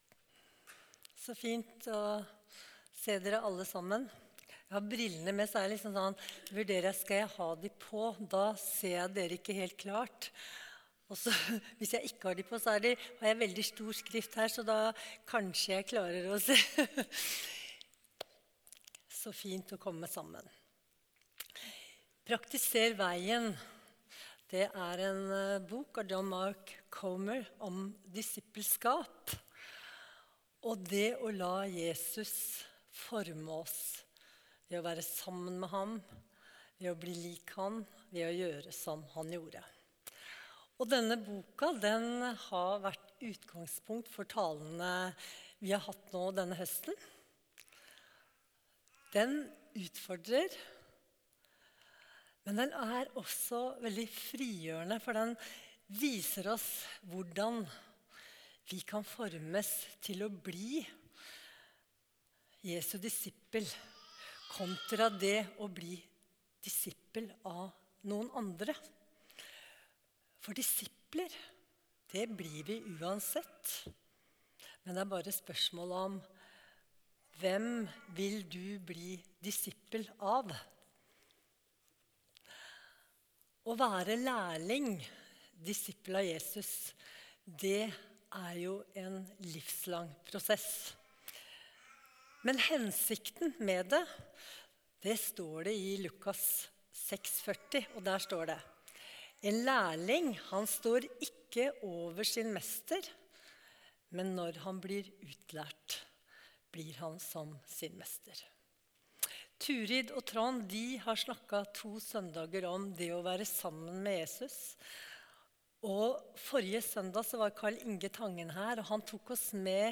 Tale